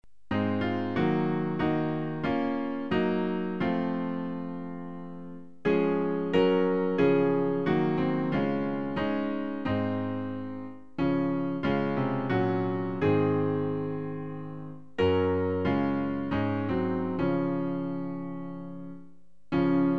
Klavier-Playback zur Begleitung der Gemeinde